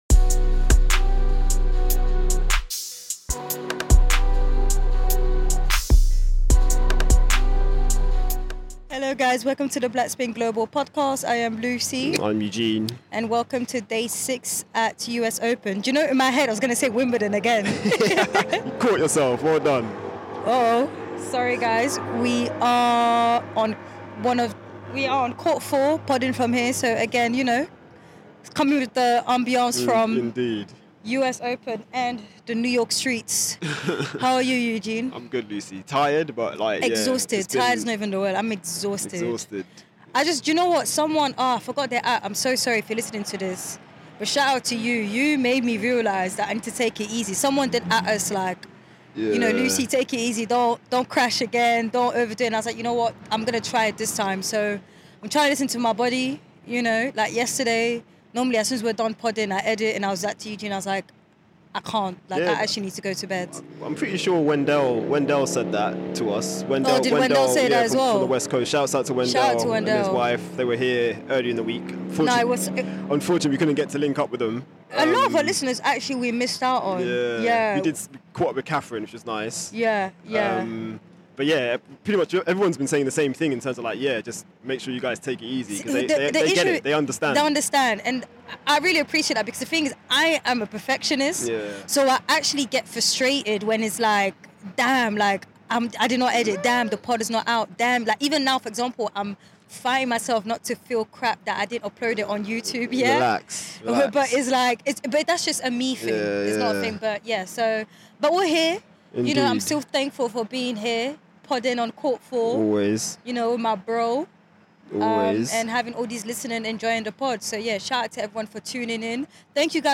Listen out for post-match presser snippets from Paolini and Diallo.